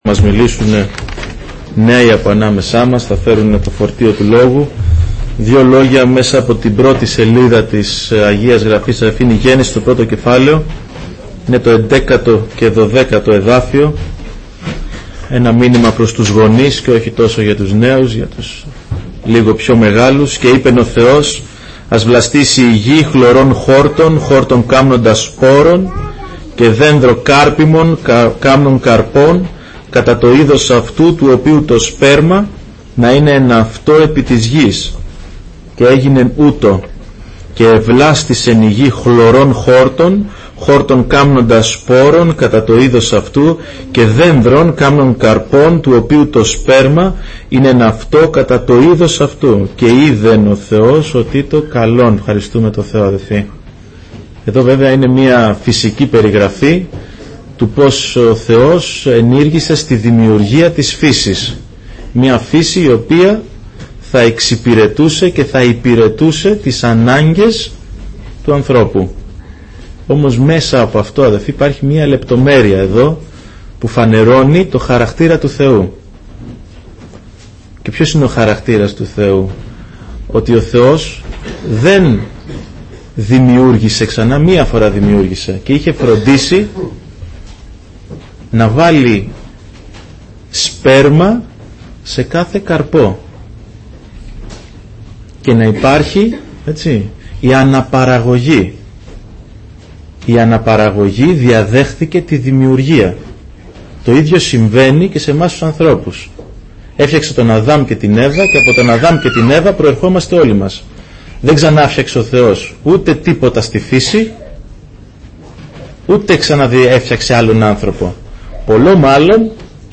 Βραδιά Νεολαίας Ομιλητής: Νεολαία Αγίας Παρασκευής Λεπτομέρειες Σειρά: Κηρύγματα Ημερομηνία: Κυριακή, 01 Δεκεμβρίου 2013 Εμφανίσεις: 439 Γραφή: Γένεσις 1:11-1:12 Λήψη ήχου Λήψη βίντεο